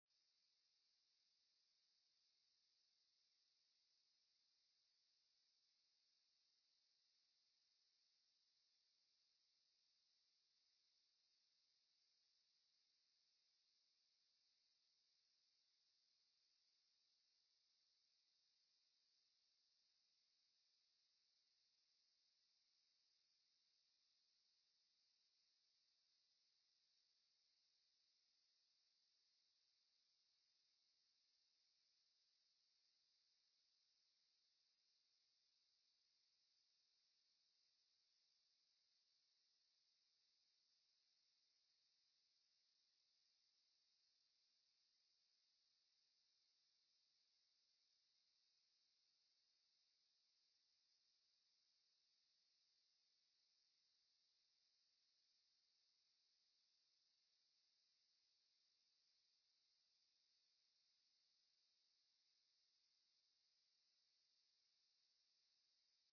66 seconds silence
vanwege de 500K attachment limiet beroerde kwaliteit